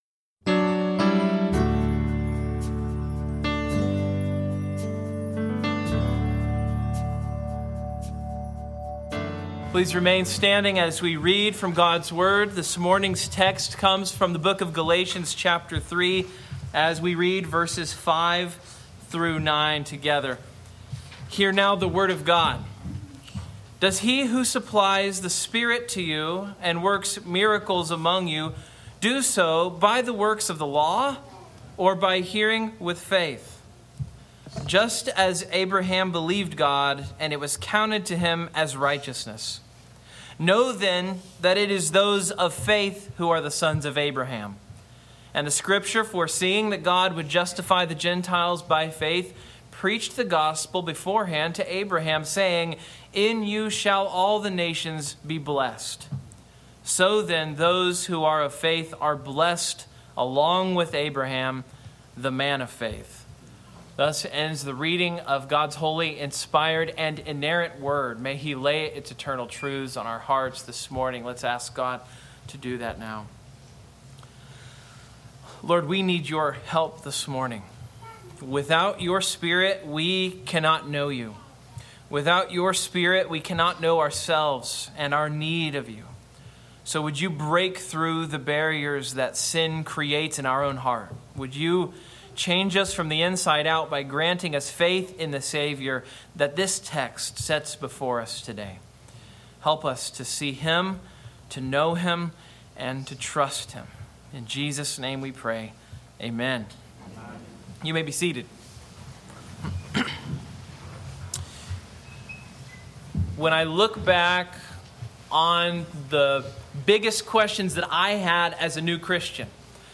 An incomplete sermon audio file was posted previously.